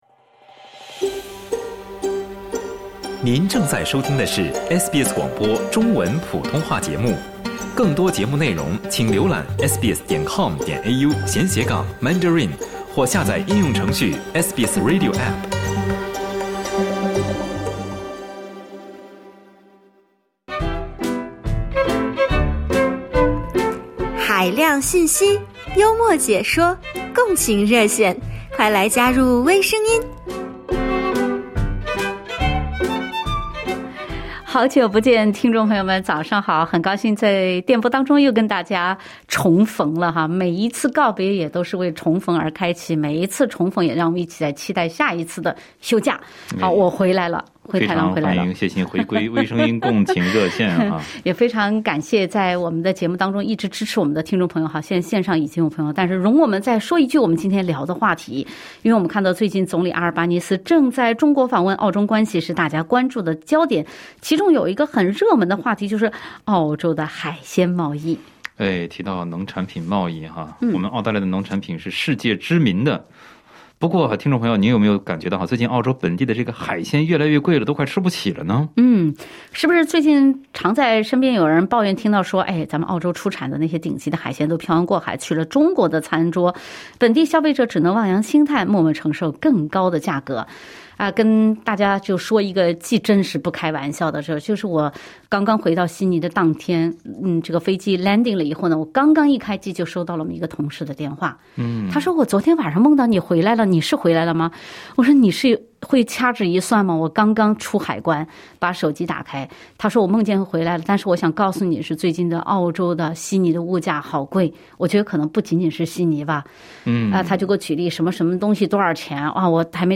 是不是最近常听人抱怨说，澳洲出产的那些顶级海鲜，都“漂洋过海”去了中国的餐桌，本地消费者只能望洋兴叹，默默承受更高的价格？热心听众在微声音共情热线一起分享，欢迎点击收听。